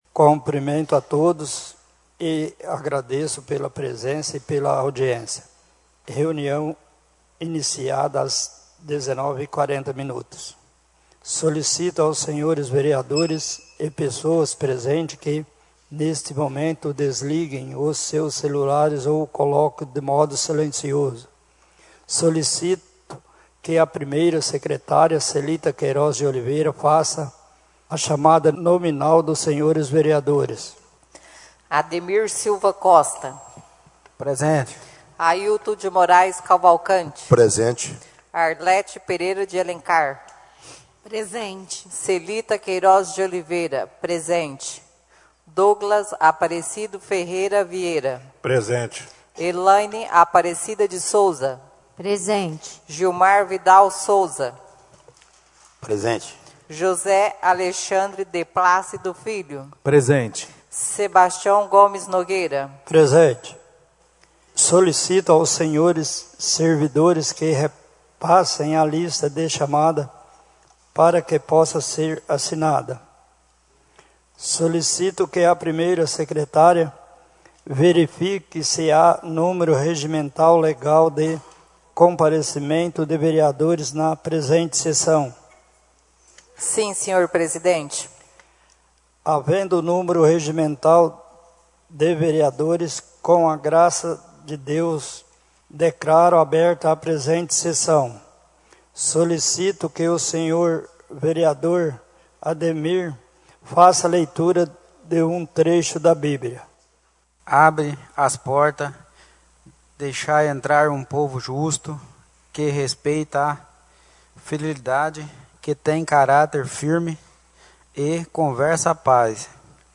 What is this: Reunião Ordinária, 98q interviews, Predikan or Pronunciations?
Reunião Ordinária